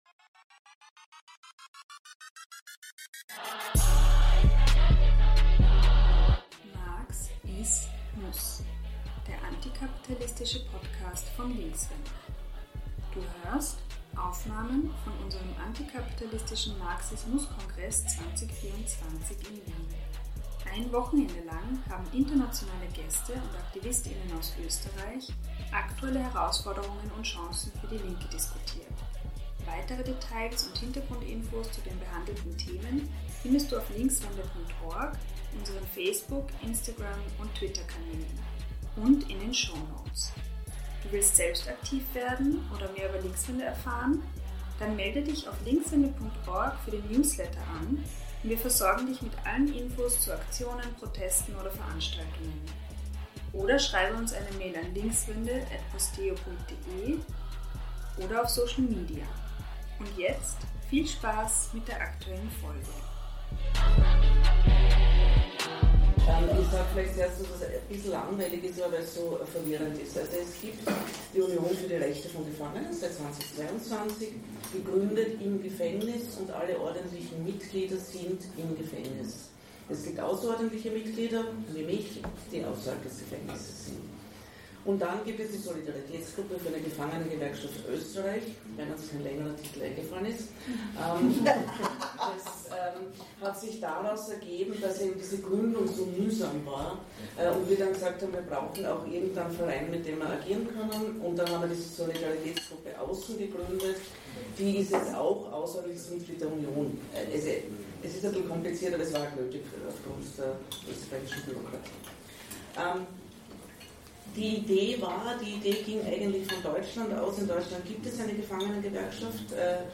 Wir entschuldigen uns für die schlechte Audio-Qualität zu Beginn der Aufnahme und hoffen, ihr bleibt dran, ca. ab Minute 03:15 werden die Hintergrundgeräusche weniger.
Wir wollen euch dieses spannende Podiumsgespräch dennoch nicht vorenthalten.